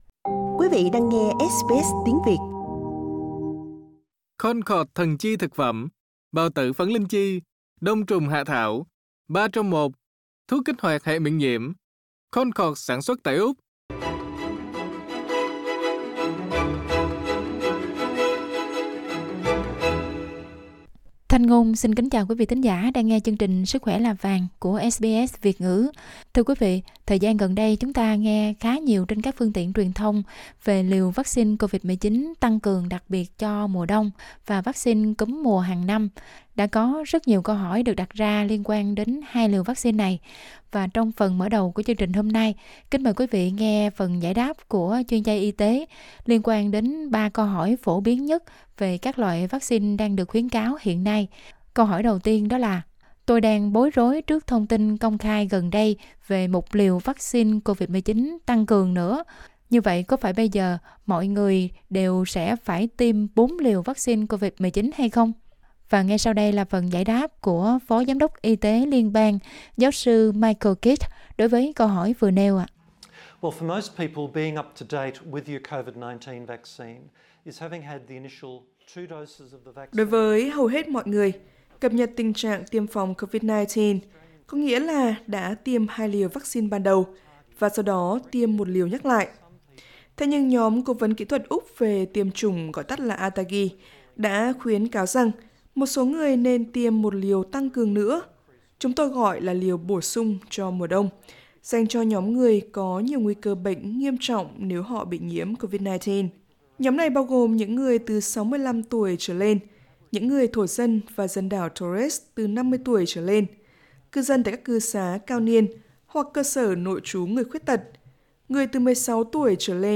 Giáo sư Michael Kidd, Phó Giám đốc Y tế Liên bang, giải đáp các câu hỏi phổ biến nhất gần đây liên quan đến liều vắc-xin COVID-19 tăng cường cho mùa đông và việc tiêm phòng cúm.